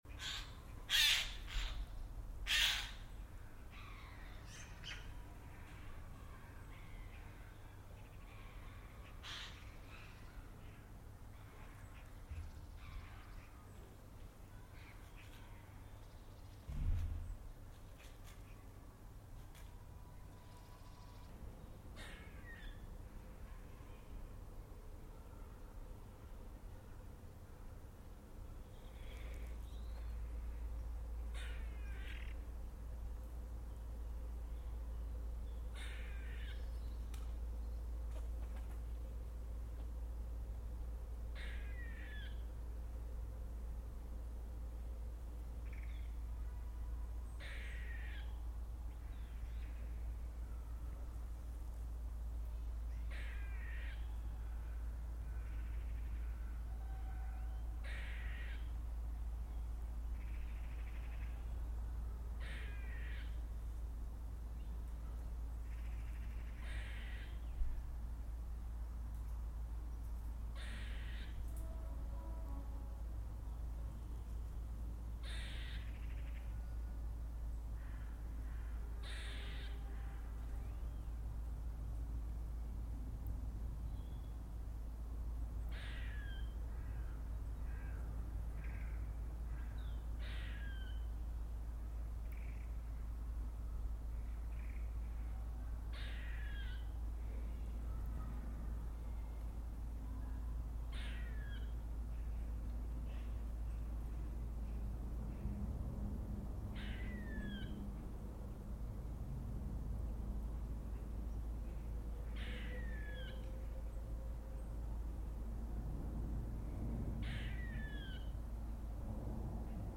It's Squirrel Apreeciation Day so here's a squirrel in my garden on 2 Jan 2021